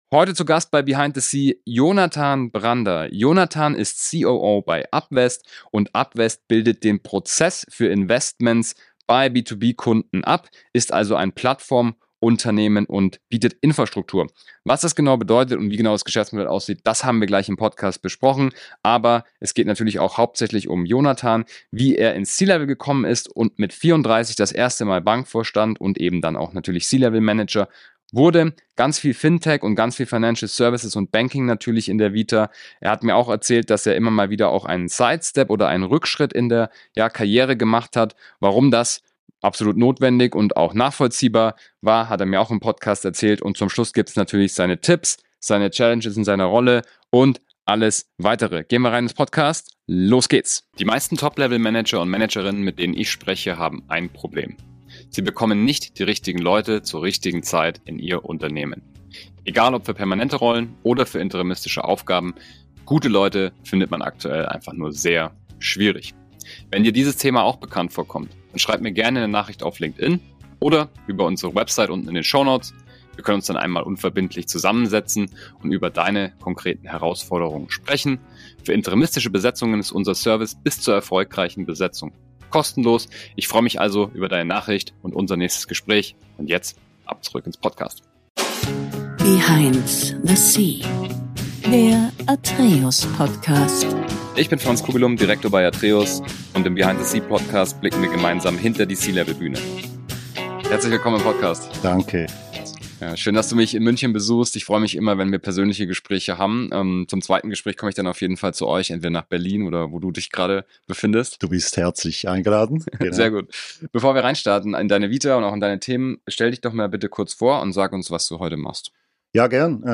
In diesem Interview spricht er über die Bedeutung von Financial Education, seinen beruflichen Werdegang und den zentralen Wert einer starken Unternehmenskultur.